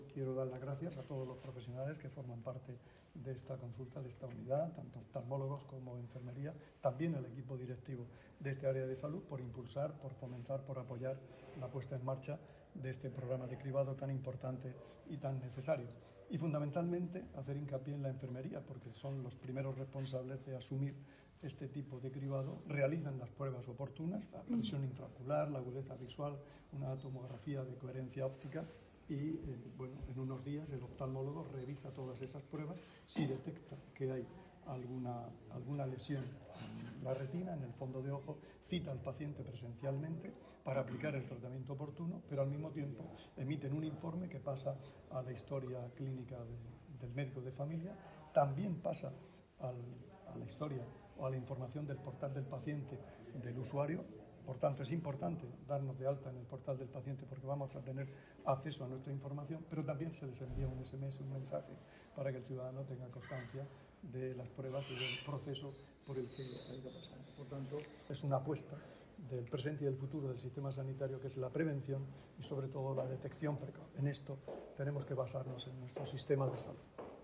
Sonido/ Declaraciones del consejero de Salud, Juan José Pedreño, sobre la puesta en marcha del cribado de retinopatía diabética en el hospital de Cieza.
El consejero de Salud, Juan José Pedreño, durante su visita al hospital Lorenzo Guirao de Cieza, que ha puesto en marcha el cribado de retinopatía diabética para la detección de lesiones que pueden causar ceguera.